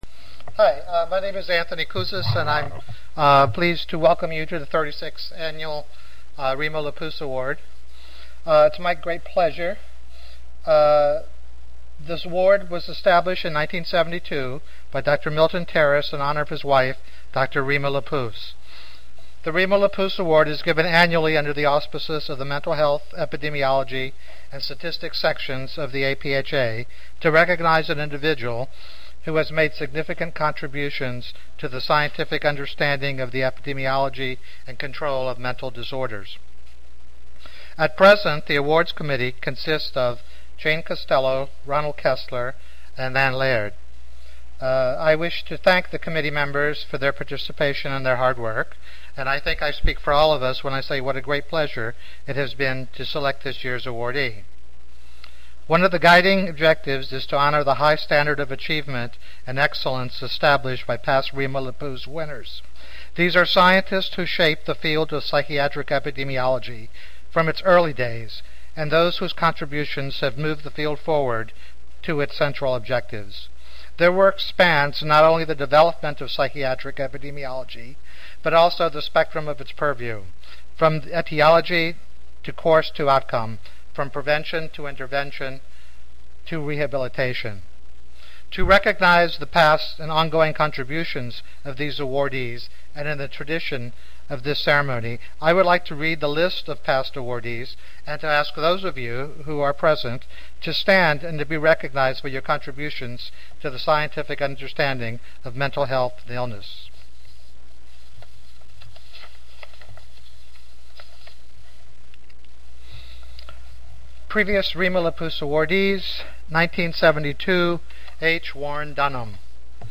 Oral Session